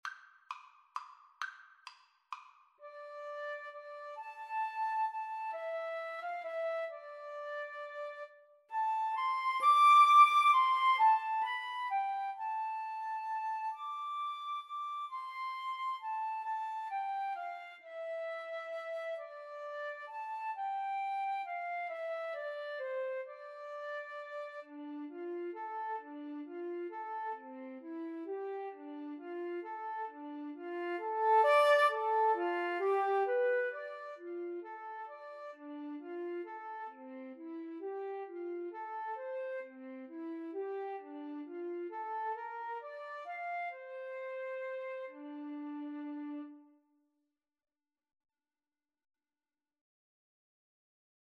Free Sheet music for Flute Duet
D minor (Sounding Pitch) (View more D minor Music for Flute Duet )
3/4 (View more 3/4 Music)
Lento =132
Flute Duet  (View more Easy Flute Duet Music)
Traditional (View more Traditional Flute Duet Music)